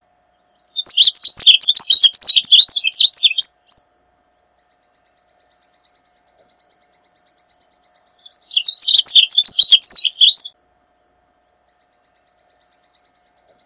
Cyanoloxia glaucocaerulea - Azulito